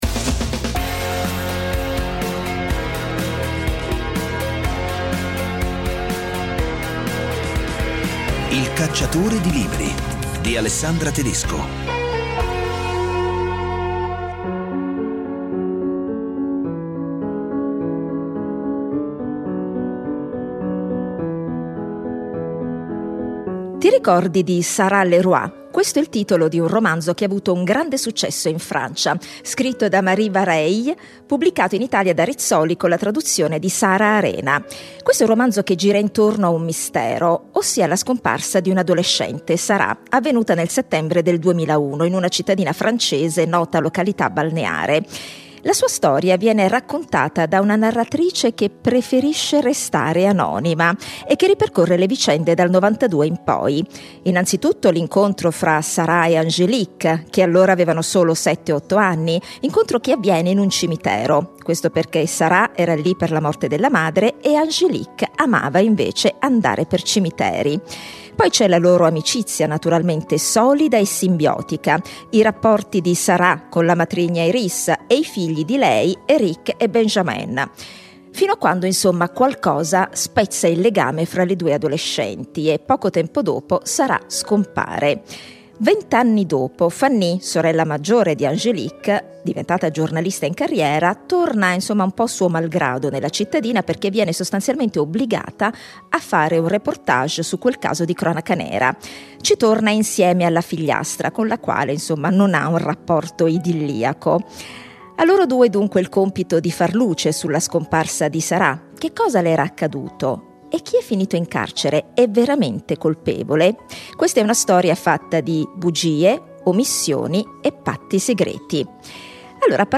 In ogni puntata due interviste dal vivo a scrittrici e scrittori, italiani e stranieri, per parlare dei romanzi: dai personaggi ai temi, dalle trame ai retroscena della scrittura.